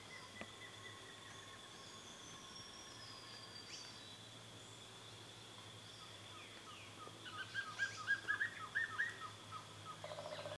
Wing-barred Piprites (Piprites chloris)
Life Stage: Adult
Location or protected area: Bio Reserva Karadya
Condition: Wild
Certainty: Photographed, Recorded vocal